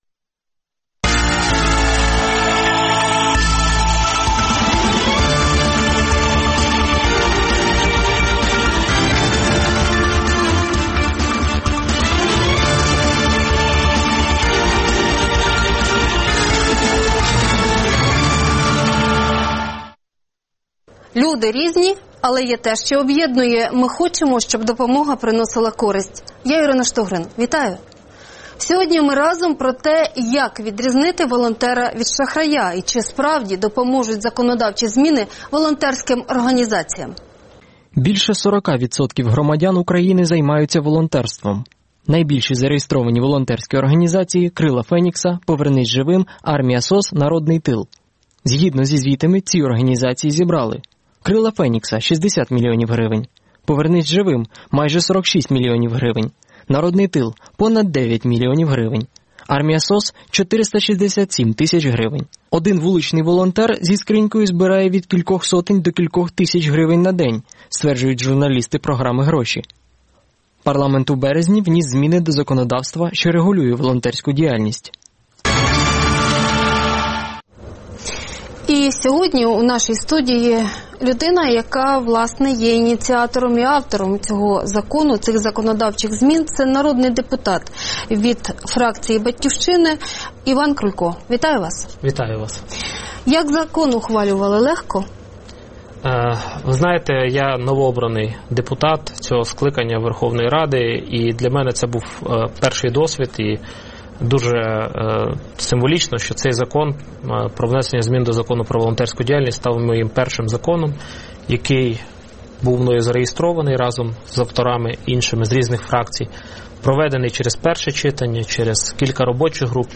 Гість: Іван Крулько, народний депутат, один із ініціаторів законодавчих змін із регулювання законодавчої діяльності